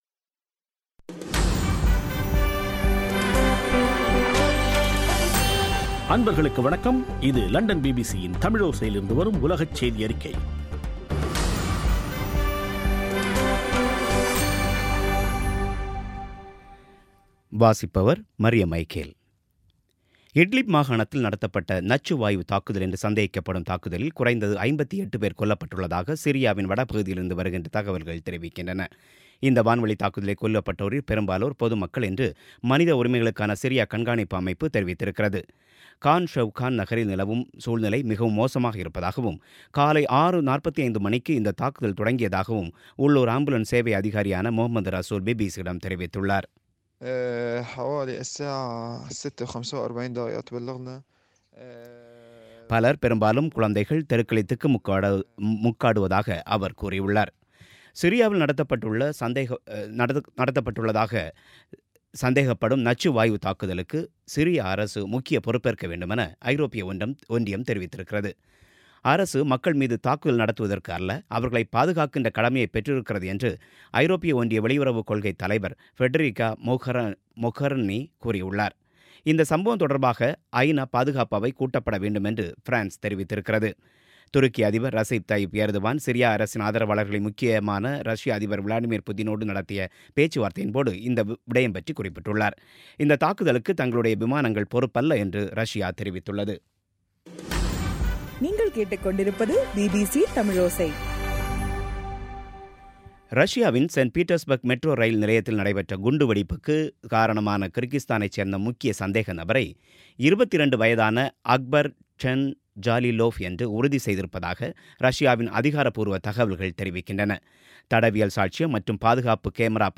பிபிசி தமிழோசை செய்தியறிக்கை (04/04/2017)